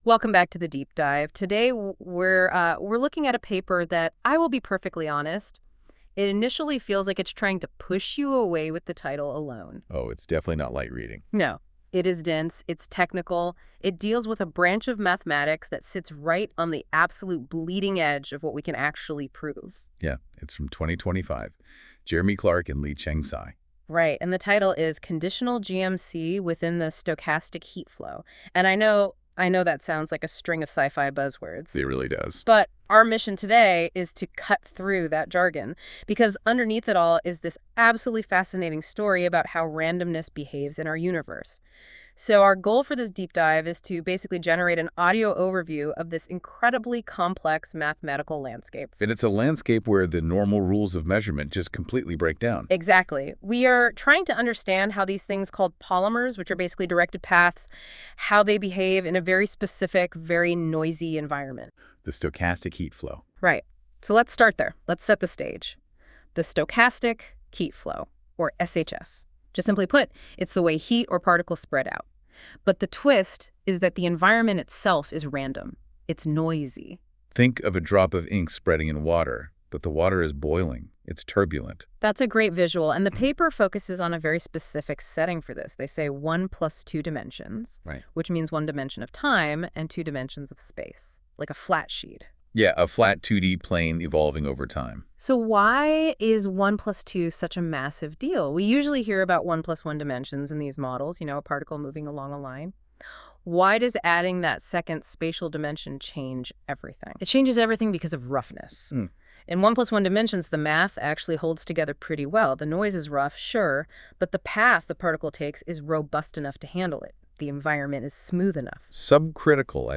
using NotebookLM powered by Google Gemini